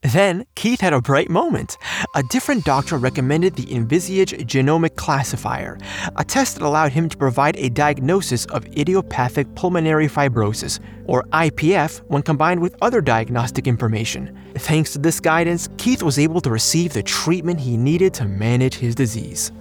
Teenager, Young Adult, Adult
medical
Medical_Explainer_(Education_Explainer).mp3